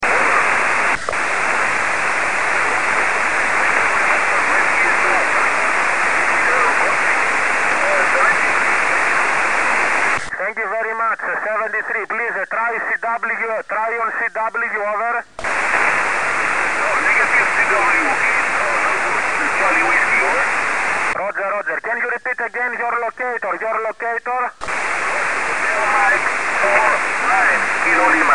in KN00NF with 5el dk7zb and ft847